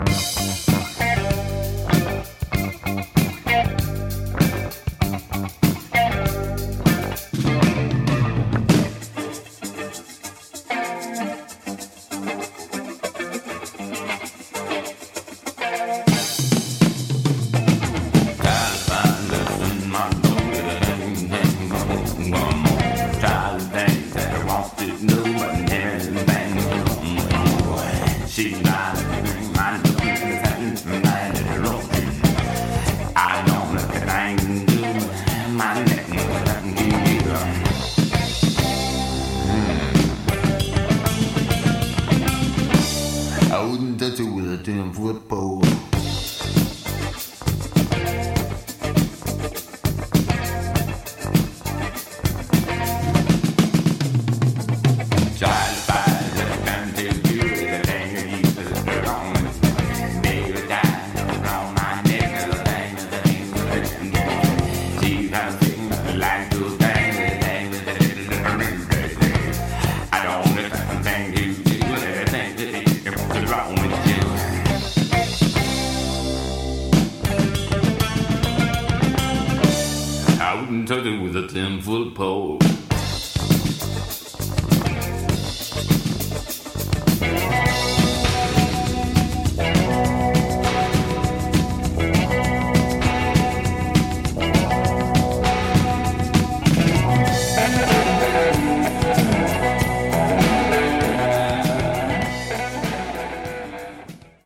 Le son est beaucoup moins hargneux, plus lisse.